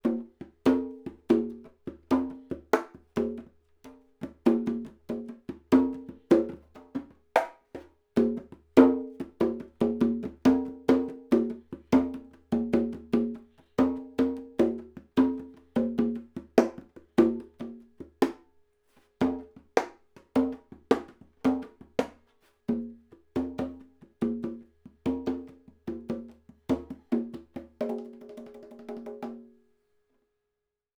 These are the raw Mid and Side files.
Mid Congas
Mid_Congas.wav